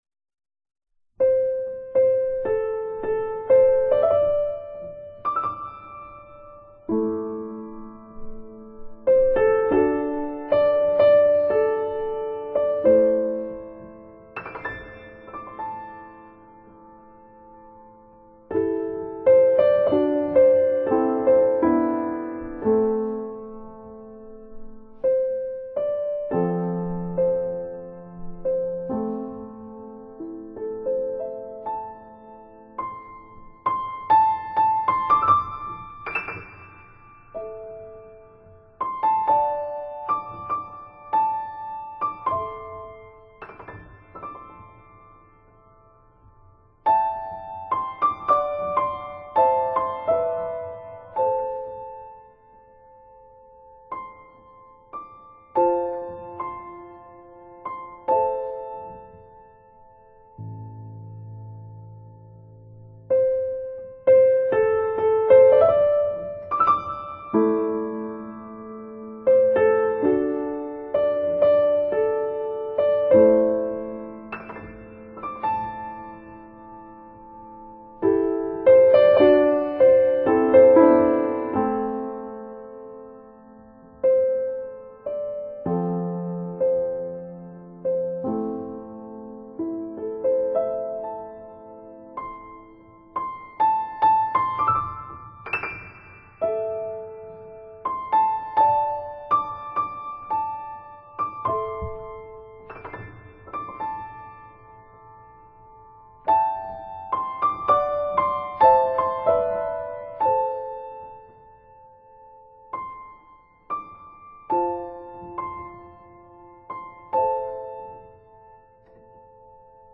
清清澹澹的琴音獻給每一顆豐富的心靈。